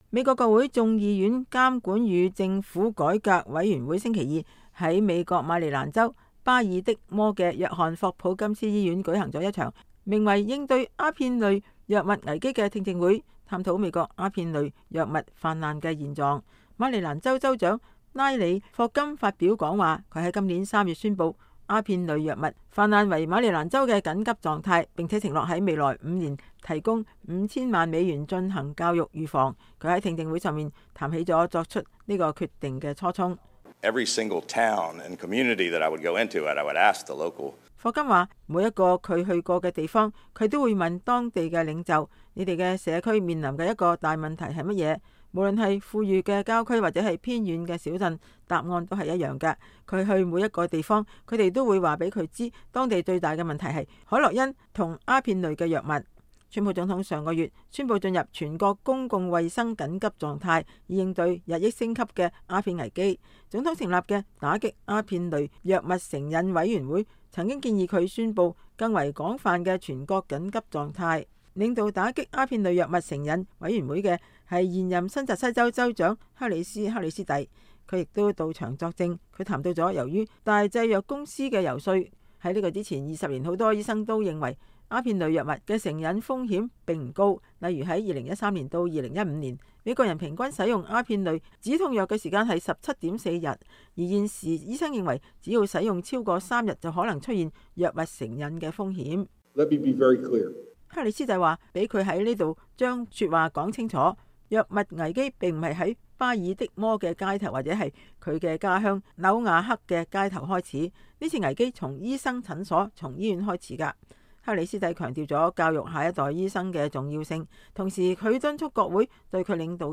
現任新澤西州州長克里斯克里斯蒂到場作證。
美國國會眾議院監管與政府改革委員會星期二在美國馬里蘭州巴爾的摩的約翰霍普金斯醫院舉行了一場名為“應對阿片類藥物危機“的聽證會，探討美國阿片類藥物氾濫的現狀。
馬里蘭州州長拉里霍甘發表講話。